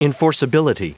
Prononciation du mot enforceability en anglais (fichier audio)
Prononciation du mot : enforceability